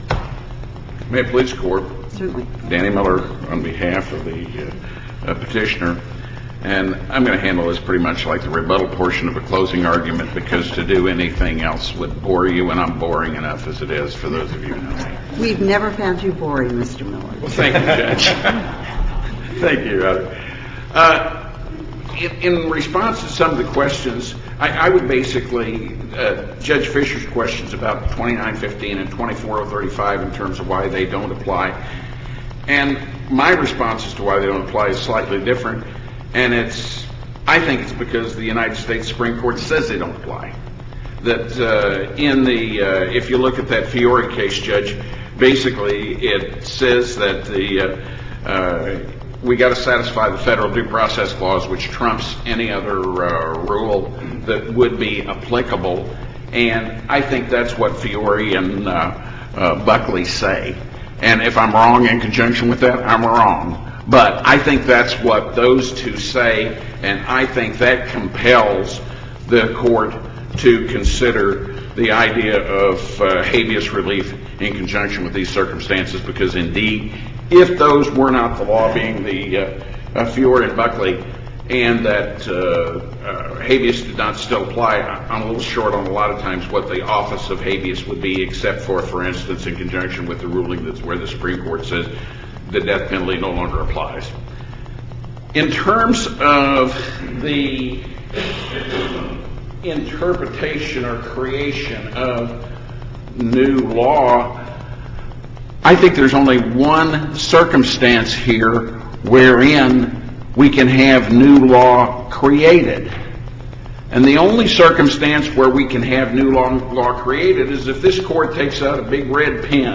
MP3 audio file of arguments in SC96159